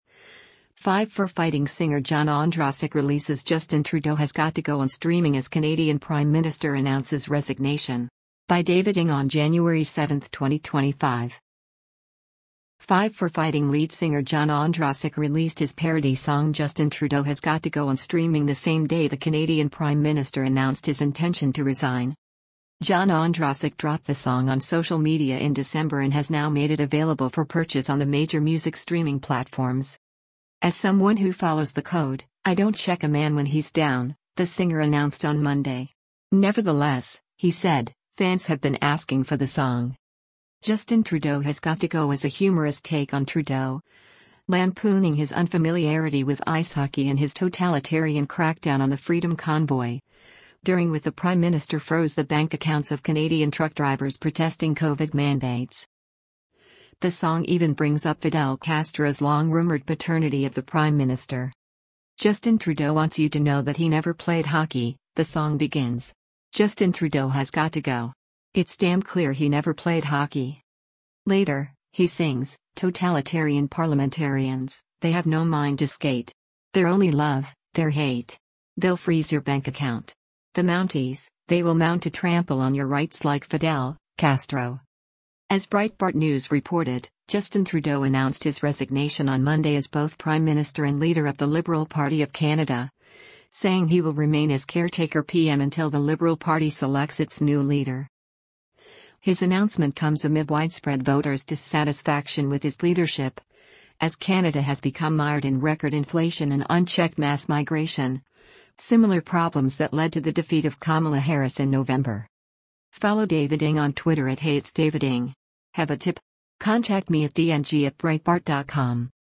parody song